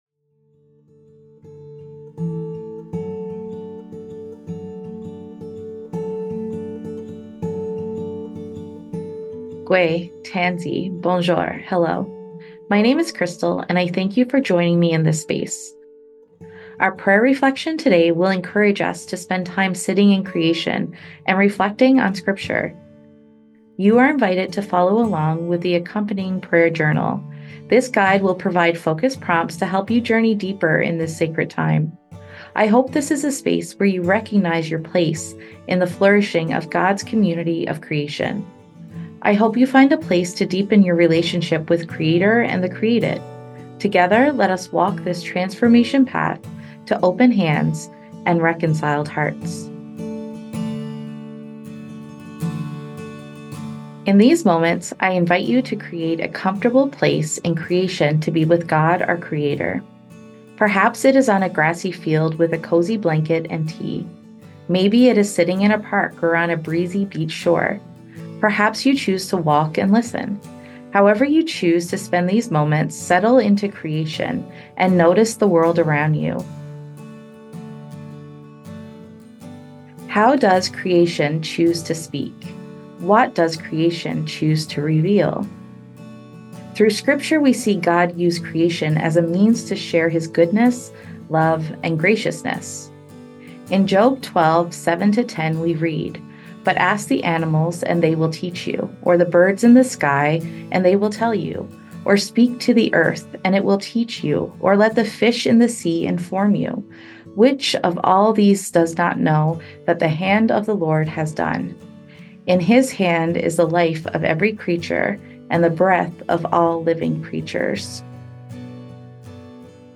Audio Prayer